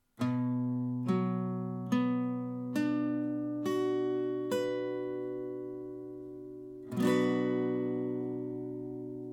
Der h-Moll-Akkord besteht aus den drei Tönen: H, D und Fis, die auch als Dreiklang bezeichnet werden.
h-Moll (Barré, E-Saite)
H-Moll, Barre E-Saite
H-Moll-Barre-E.mp3